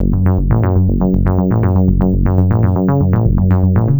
Rumblesaw F# 120.wav